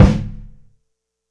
Simply Molded Kick 3k.wav